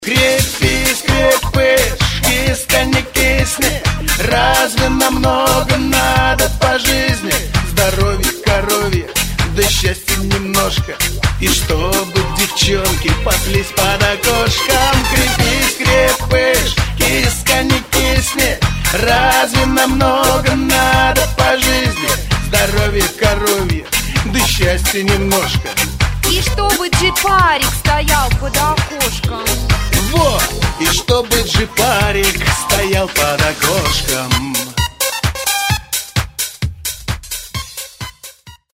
Категория: Шансон | Дата: 10.12.2012|